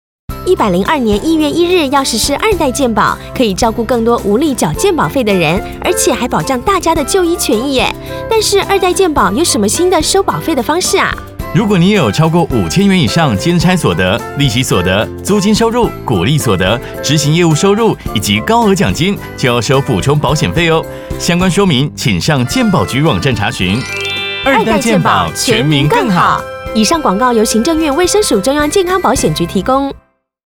廣播